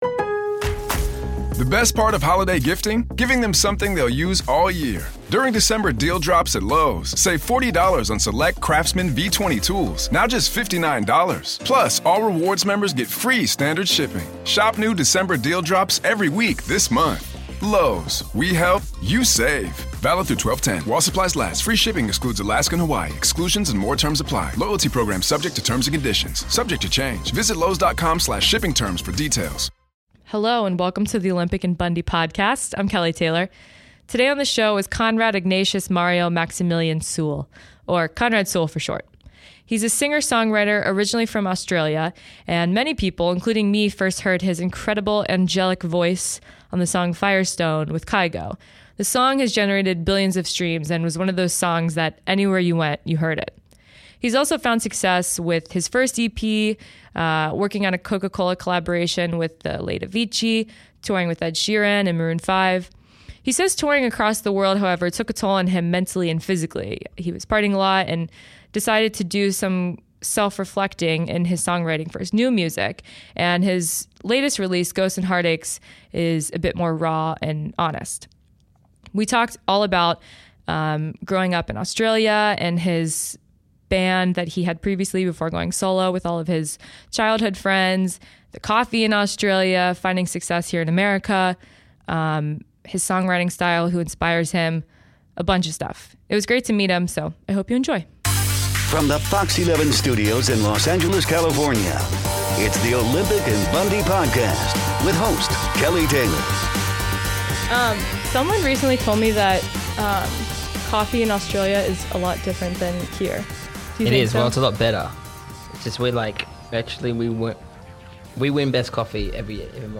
Conrad joined me on Olympic & Bundy to talk about growing up in Australia, his former band Sons of Midnight, the story behind "Firestone," songwriting, his musical influences, his latest release "Ghosts & Heartaches," his great hair and a lot more!